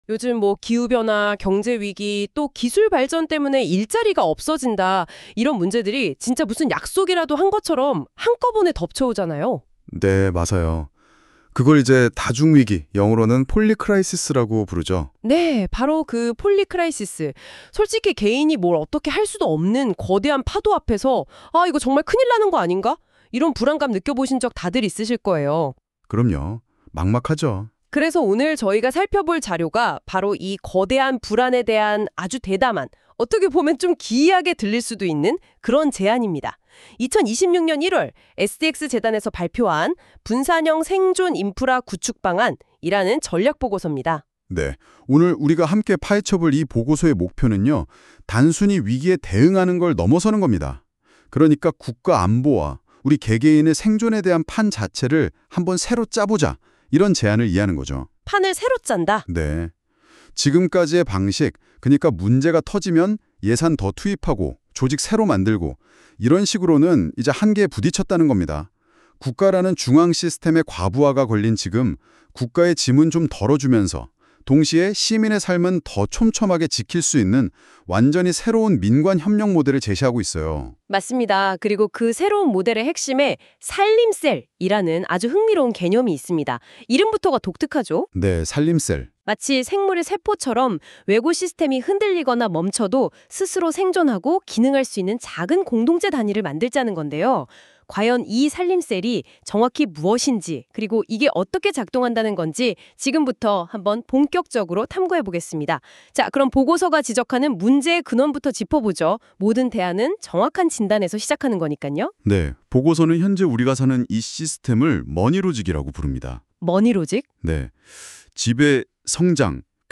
(오디오북) v1,2 분산형 생존 인프라 구축 방안.m4a